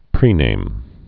(prēnām)